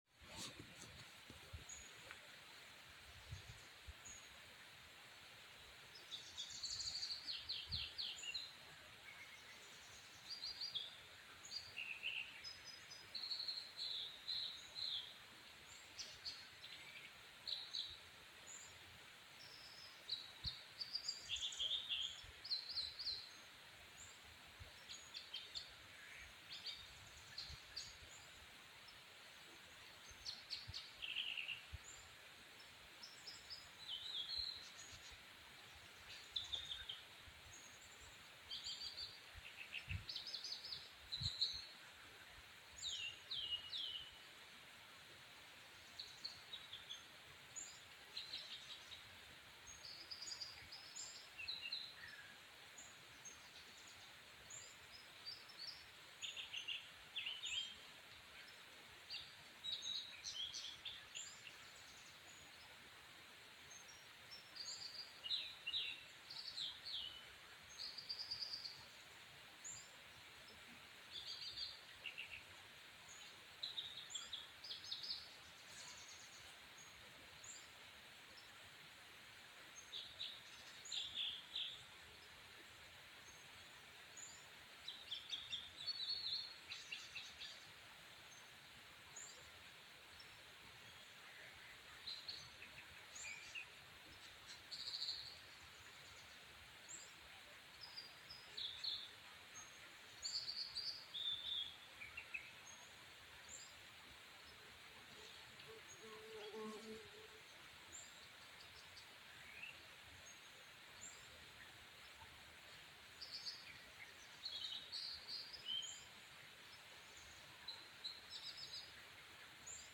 The soundscape at the trailhead. It must be summer.
So, what should I use to trim off the clicks start and finish, bring up the levels, whatever else needs done?